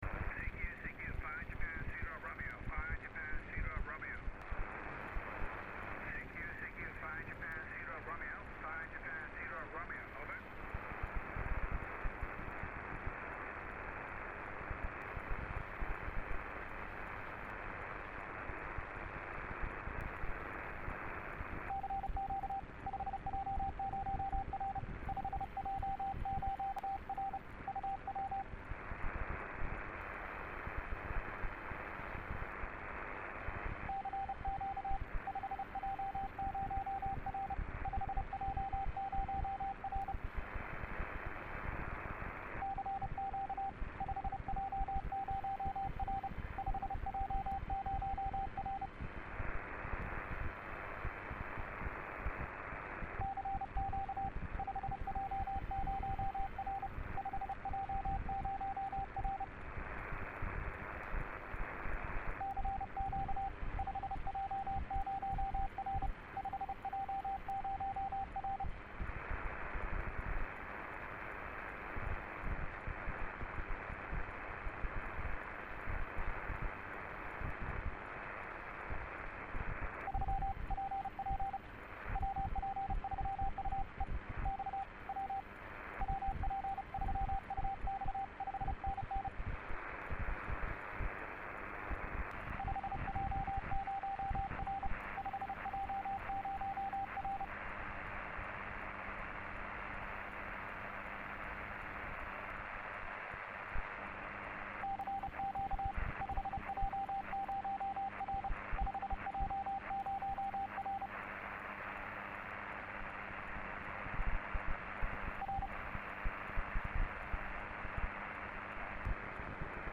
5J0R - CQ 6m, CW/SSB